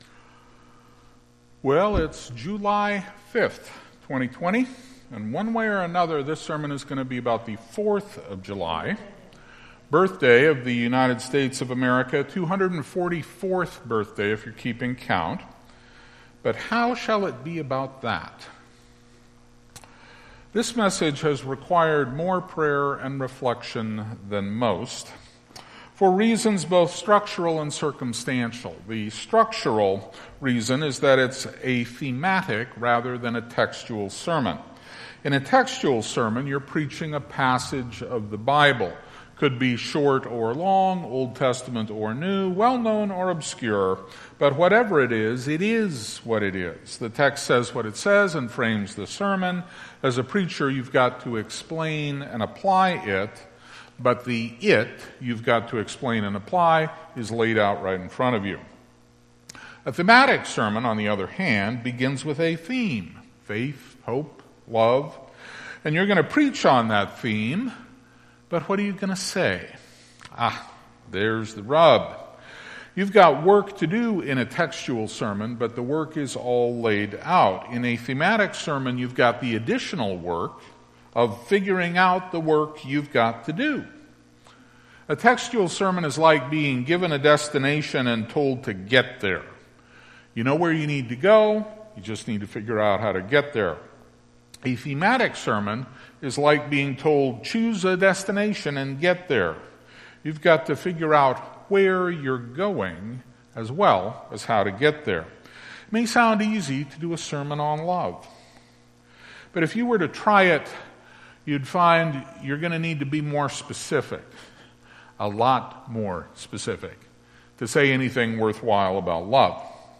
Audio Sermon
Sun-July-5-2020-service.mp3